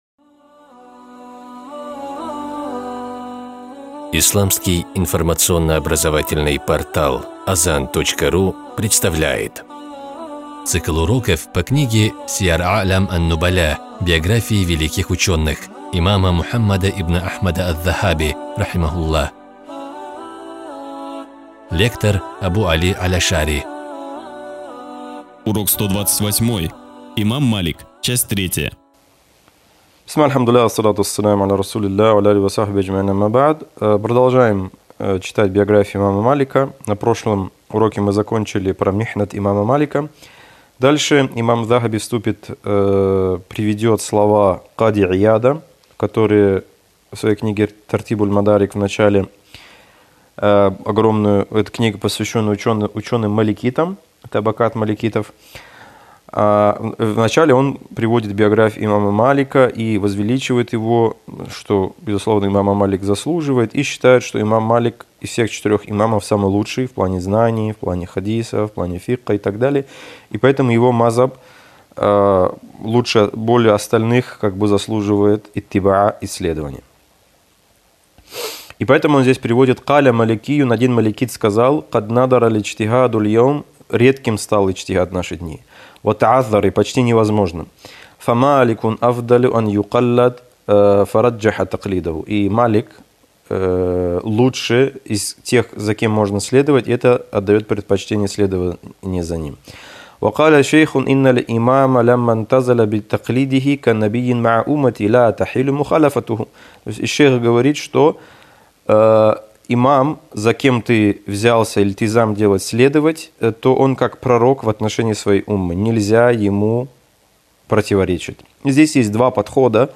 Цикл уроков по книге великого имама Аз-Захаби «Сияр а’лям ан-нубаля». Биографии исламских ученых для мусульман — не просто история, но и пример для подражания верующих, средство для улучшения их нрава.